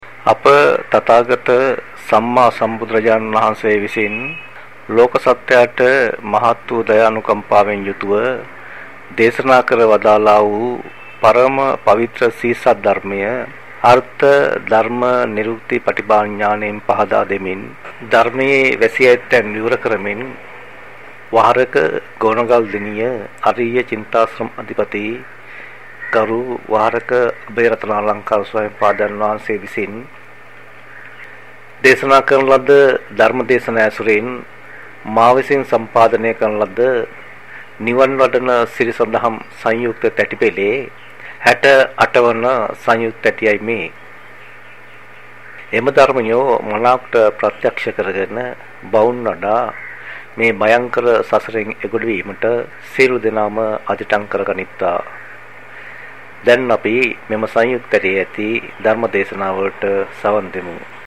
වෙනත් බ්‍රව්සරයක් භාවිතා කරන්නැයි යෝජනා කර සිටිමු 01:02 10 fast_rewind 10 fast_forward share බෙදාගන්න මෙම දේශනය පසුව සවන් දීමට අවැසි නම් මෙතැනින් බාගත කරන්න  (954 KB)